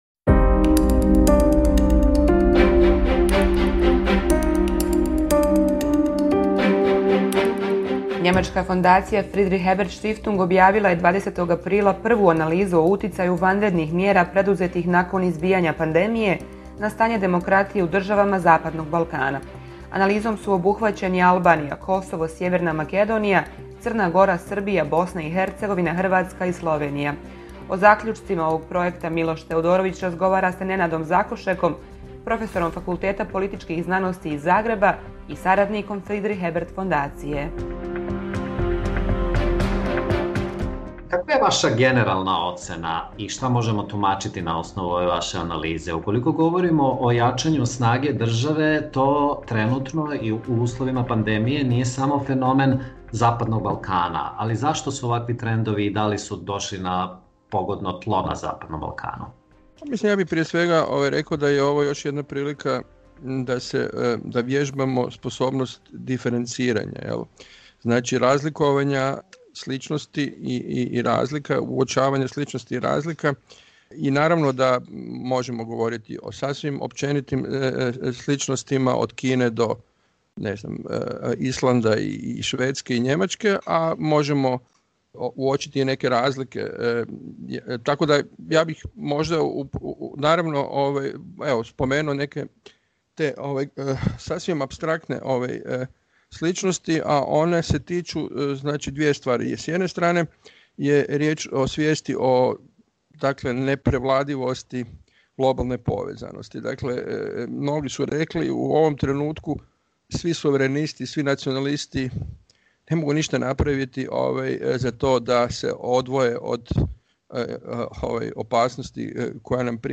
Intervju